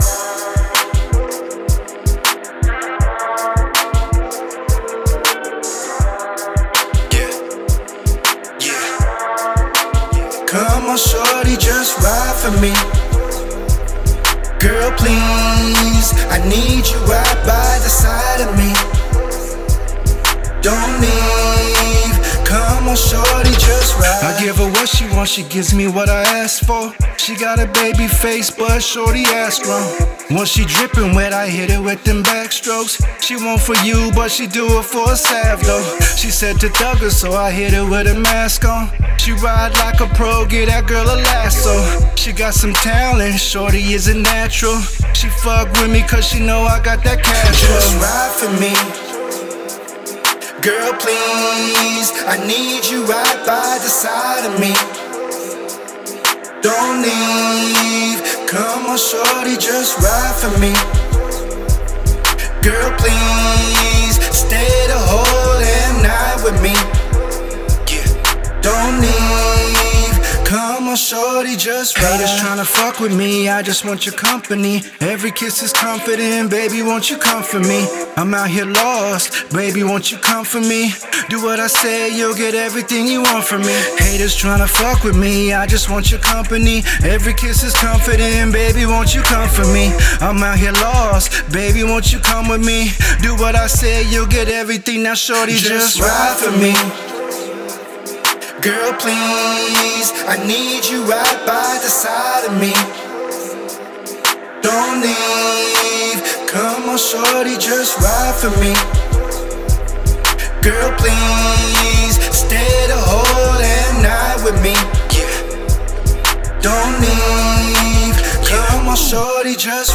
Hiphop
Native American Hip Hop MC